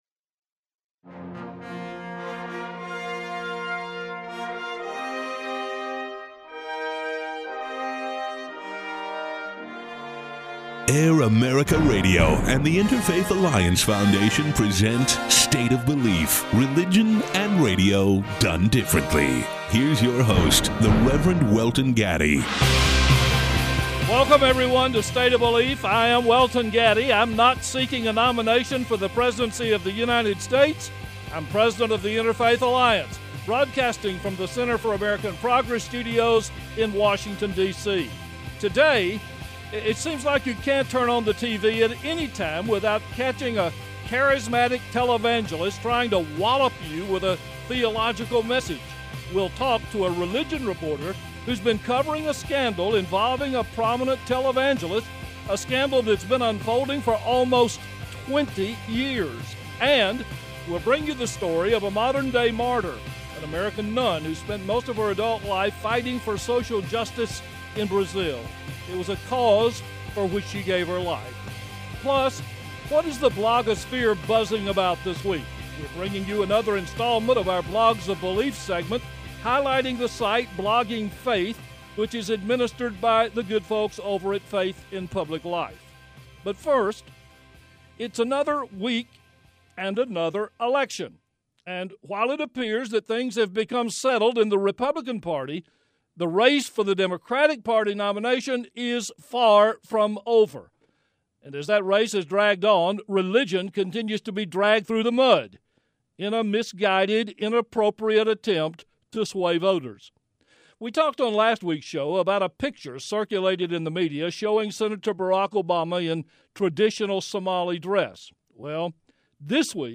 State of Belief Religion and radio, done differently Brought to you by The Interfaith Alliance Foundation Saturday morning 10am-11am ET and Sunday evening 7pm-8pm ET Air America Radio Network